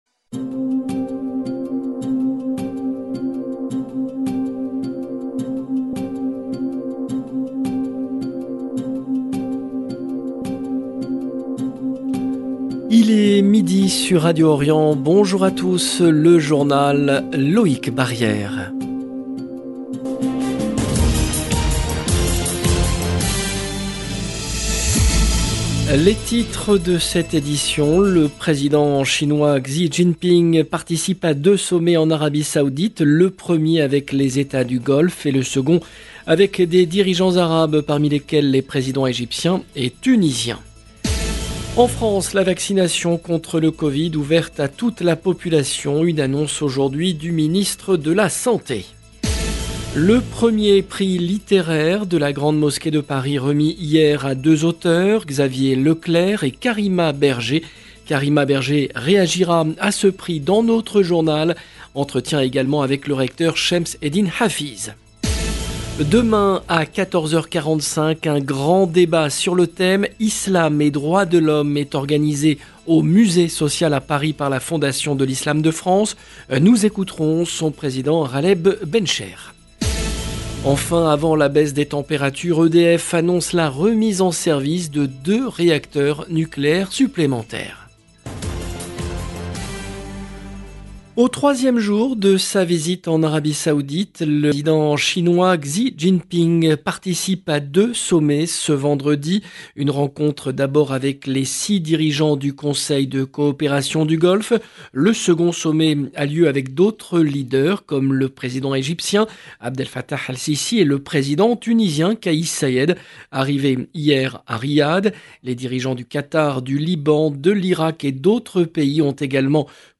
LE JOURNAL EN LANGUE FRANCAISE DE MIDI DU 9/12/22